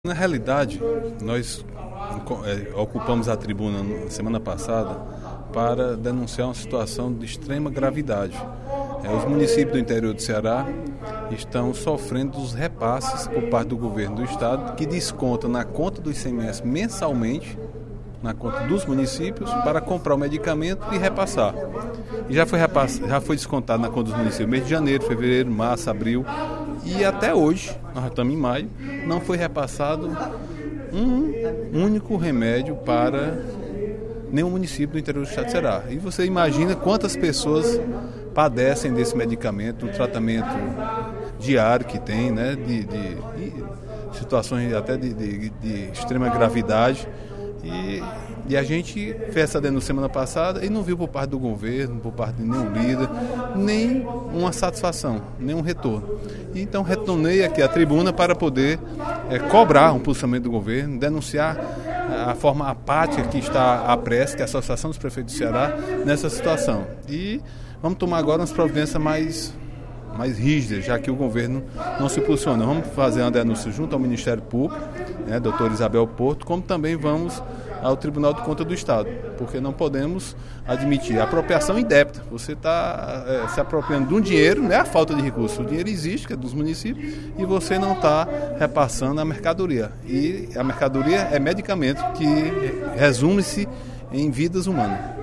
O deputado Agenor Neto (PMDB) cobrou, em pronunciamento no segundo expediente da sessão plenária desta quarta-feira (04/05), o Governo do Estado por atrasar o repasse de medicamentos para os municípios do Interior.